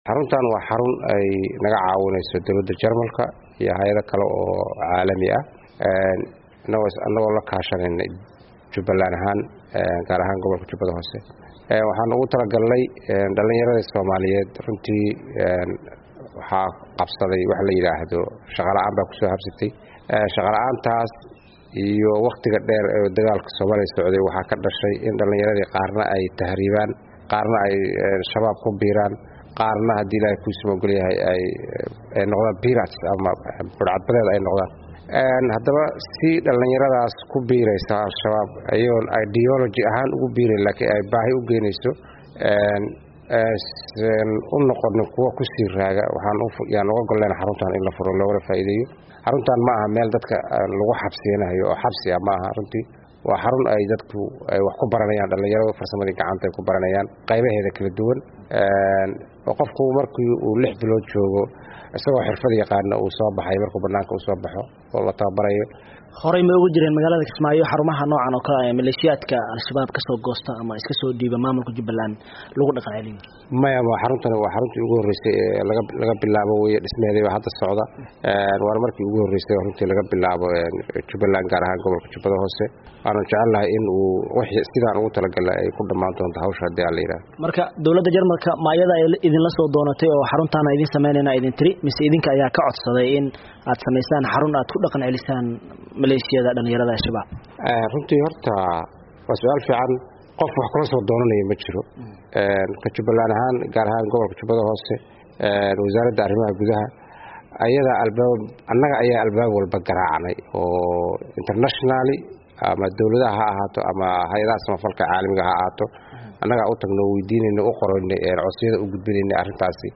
Wareysi: Guddoomiyaha Jubbada Hoose